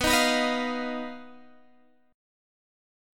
BMb5 chord